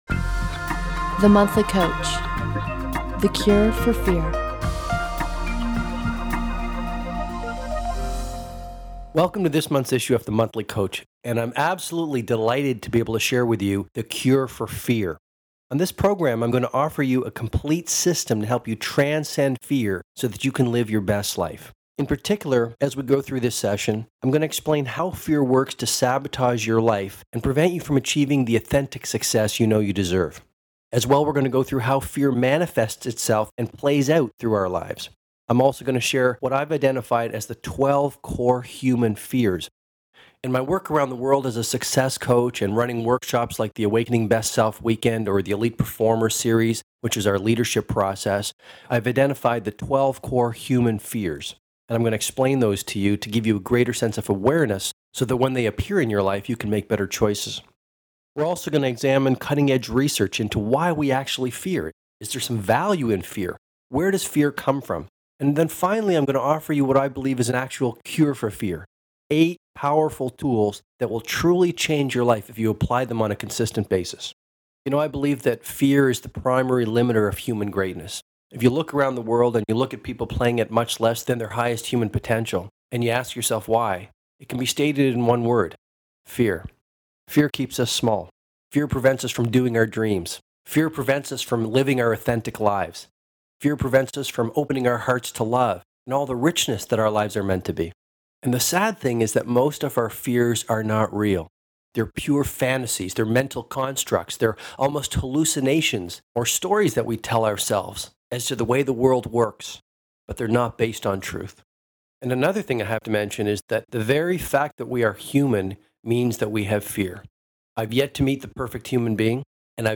Cure-For-Fear-Audiobook.mp3